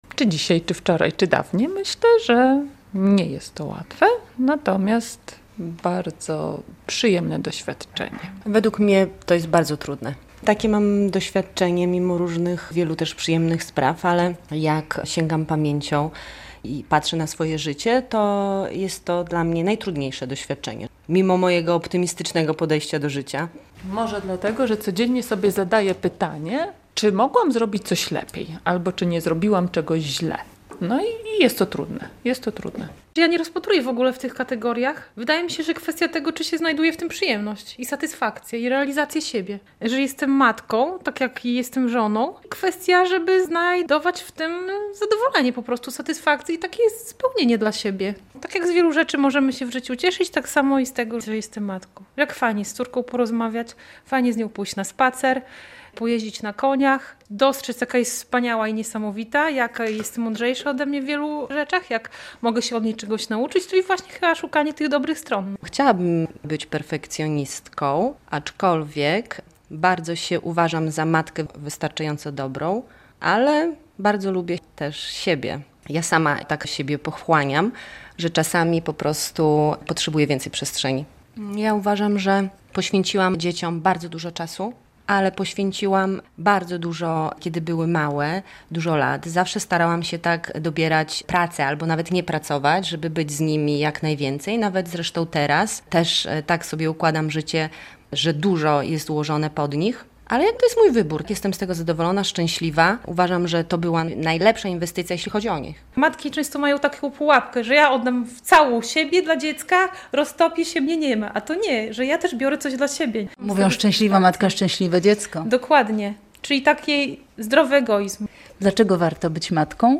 I na to - w Dniu Matki - zwracają też uwagę białostoczanki, które spotkały się na rodzicielskich warsztatach.